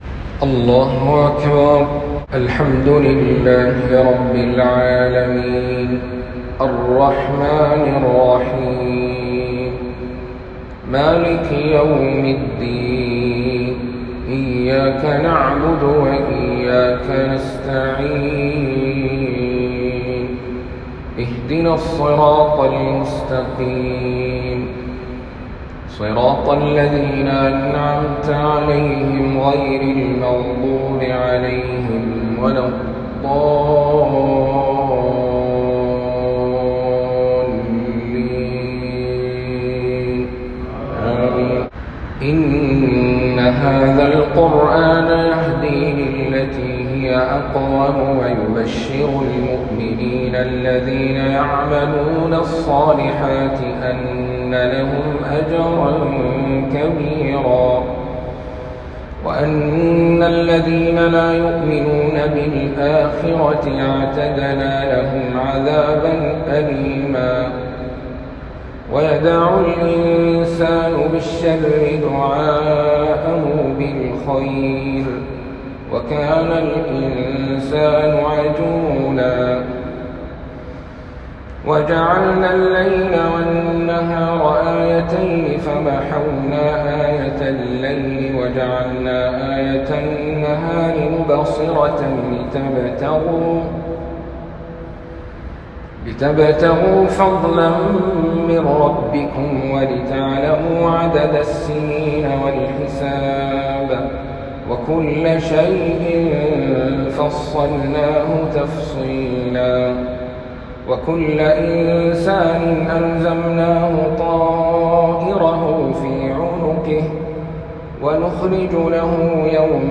فجرية الإثنين بمسجد القبلتين